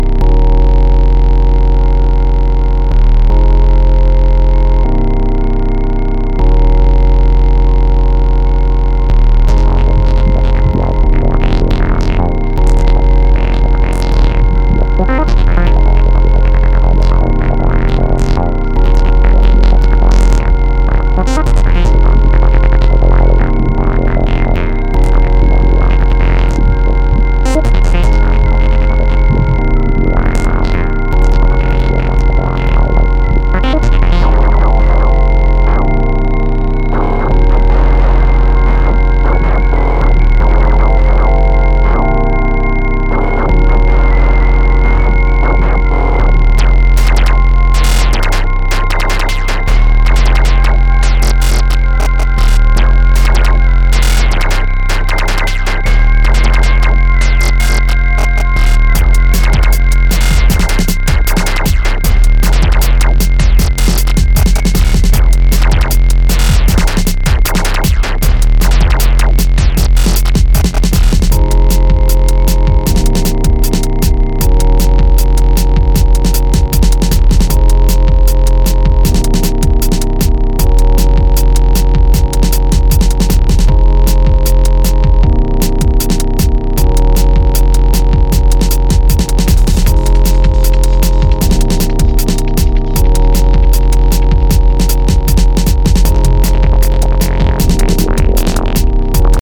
iconic and mesmerizing dark break journey
pulsating beats